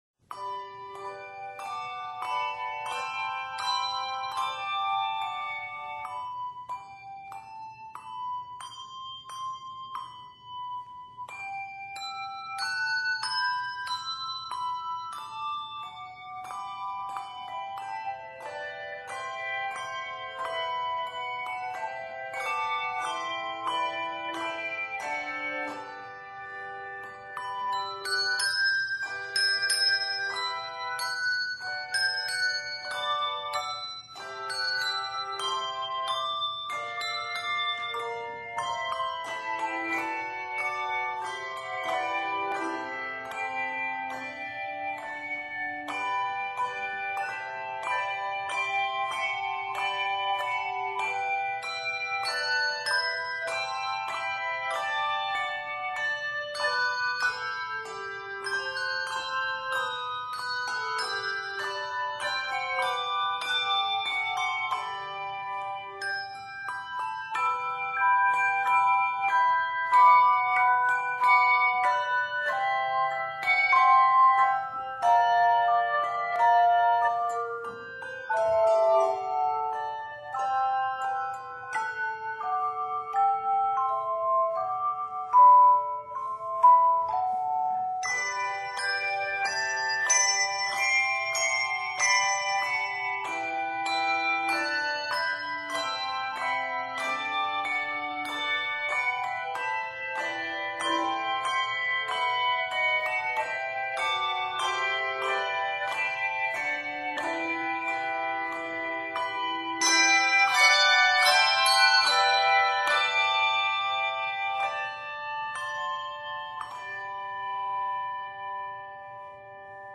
It can be performed on either handbells or handchimes.